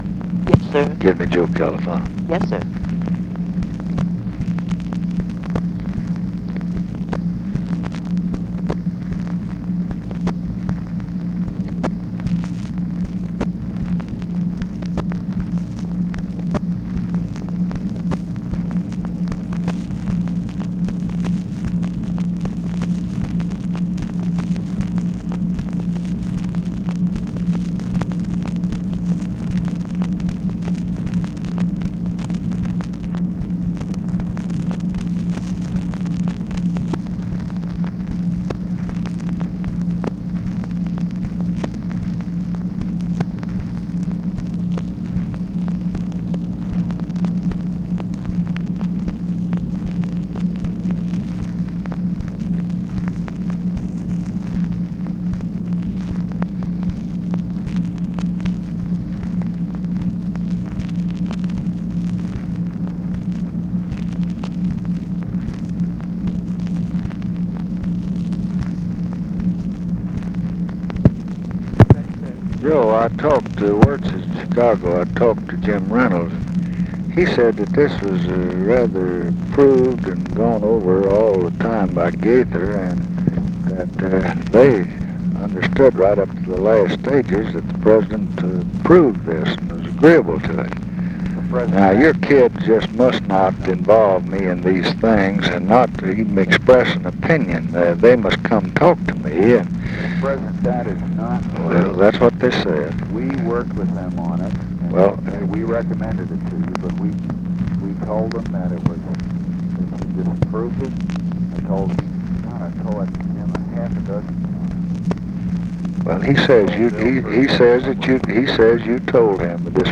Conversation with JOSEPH CALIFANO and TELEPHONE OPERATOR, October 23, 1968
Secret White House Tapes